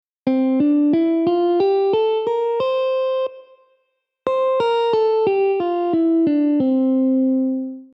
1-2-3-4-5-6-b7
(C-D-E-F-G-A-Bb)
Mixolydian-AUDIO.mp3